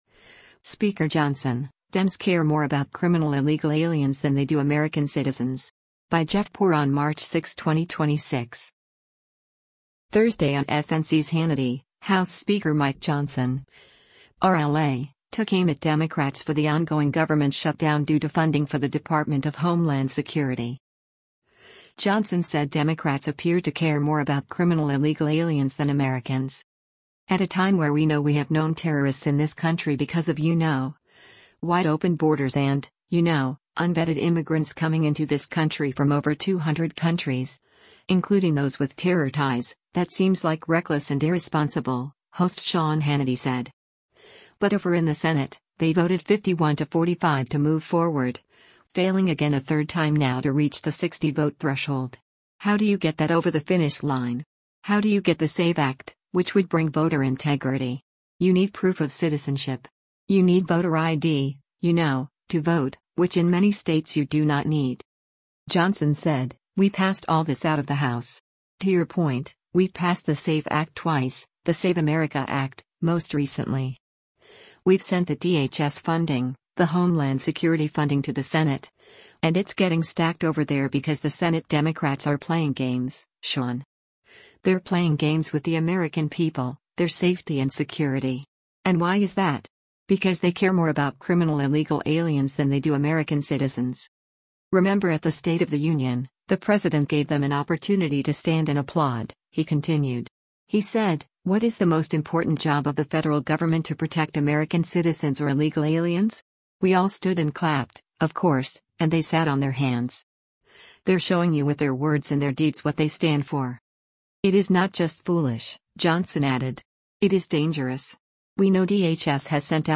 Thursday on FNC’s “Hannity,” House Speaker Mike Johnson (R-LA) took aim at Democrats for the ongoing government shutdown due to funding for the Department of Homeland Security.